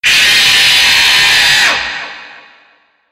Funtime Golden Sonic Jumpscares Sound 1 Botão de Som
Sound Effects Soundboard2 views